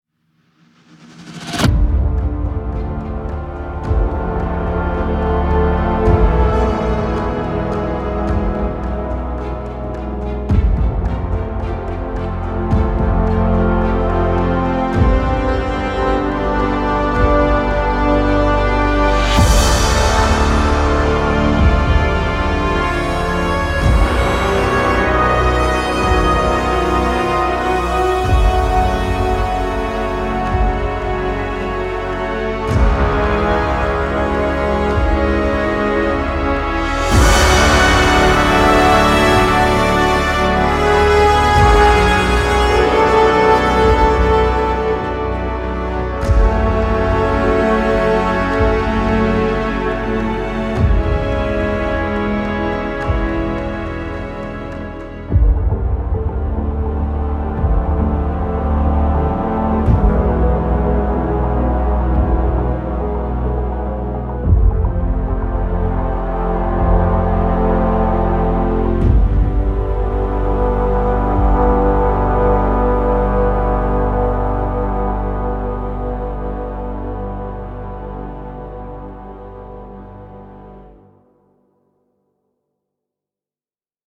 打击乐器
弦乐
STRING ENSEMBLE 拥有紧凑、饱满而自然的音色，并配备先进的演奏控制功能，可呈现丰富的细节和清晰的音质。
黄铜
木管乐器